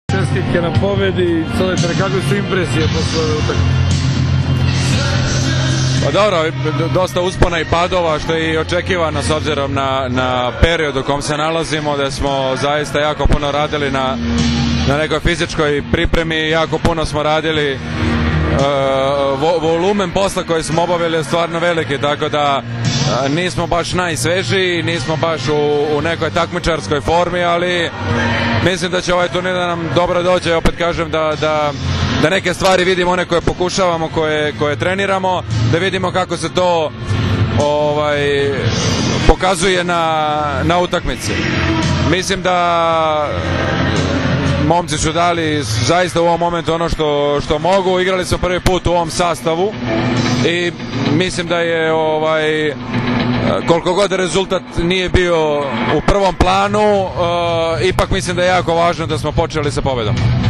IZJAVA NIKOLE GRBIĆA